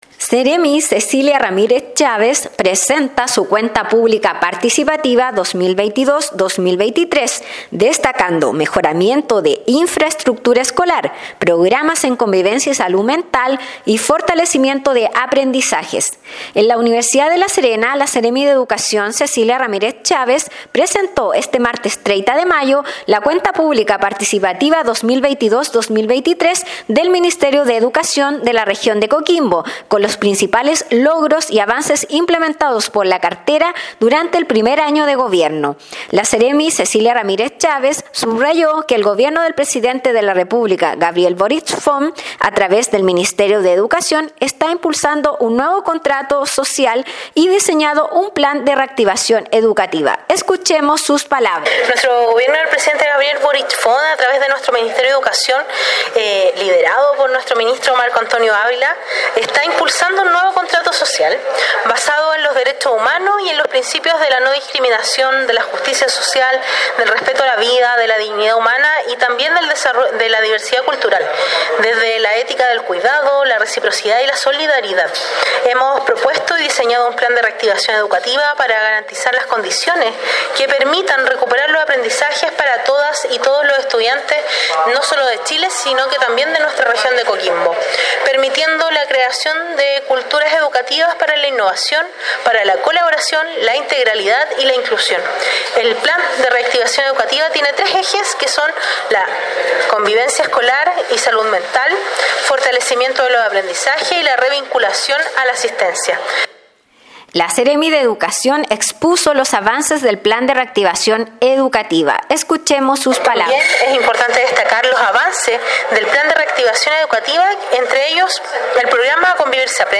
Despacho-Radial-Cuenta-Publica-Seremi-de-Educacion_.mp3